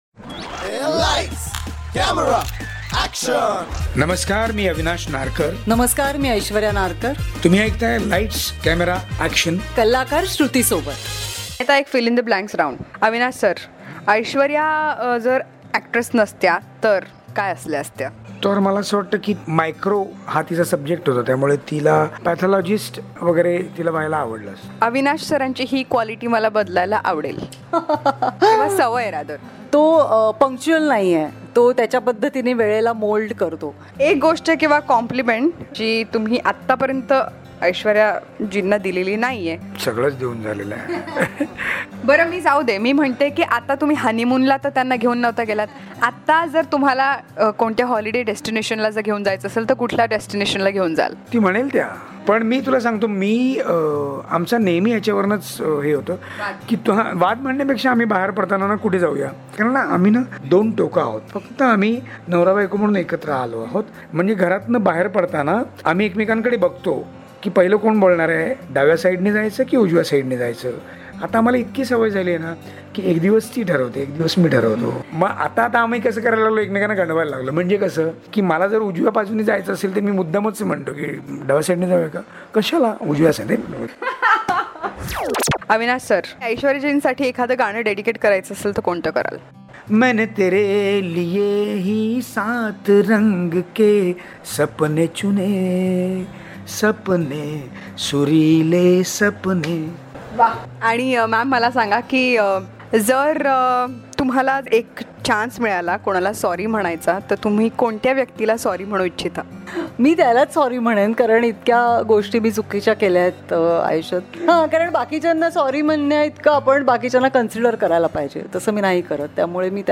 Love is a beautiful journey where every step is a destination and every moment eternity .. Listen to this podcast as the cutest romantic couple talks about their journey of life exclusively on Lights Camera Action.